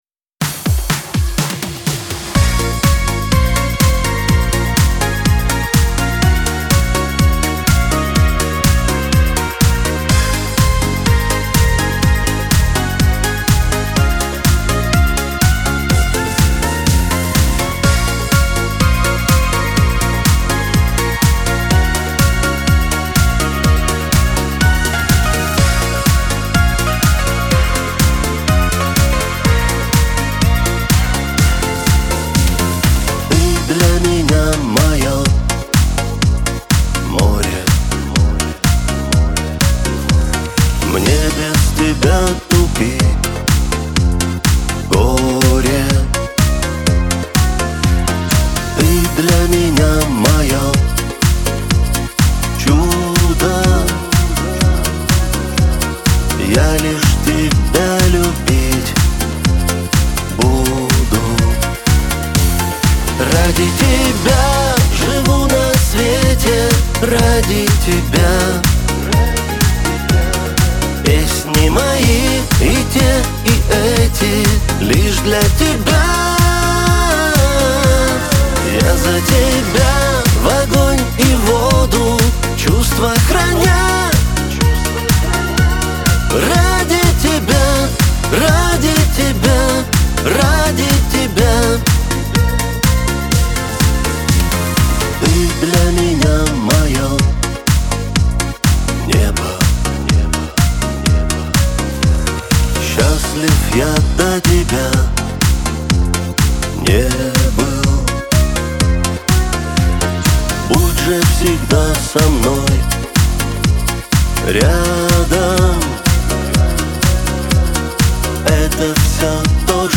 Жанр: Шансон, Поп, Русская Эстрада